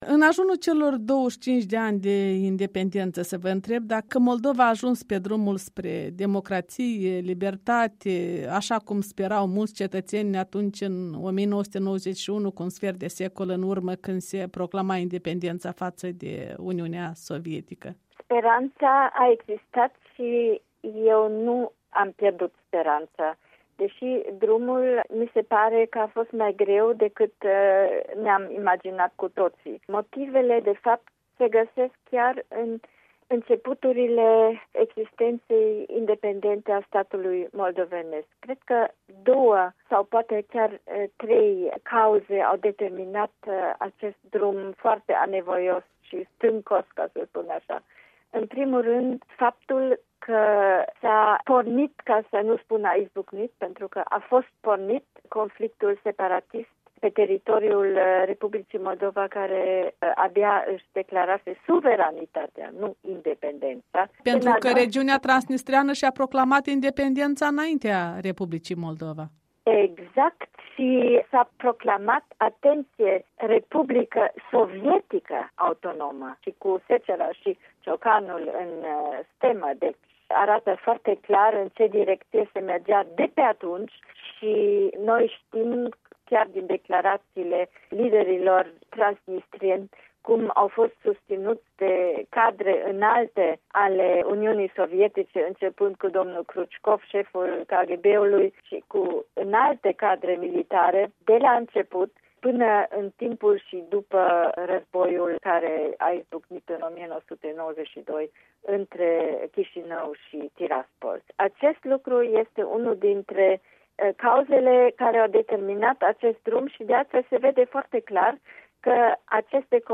Un interviu despre Moldova la un sfert de veac de independență cu profesoarea de științe politice în Germania.